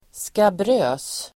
Ladda ner uttalet
Uttal: [skabr'ö:s]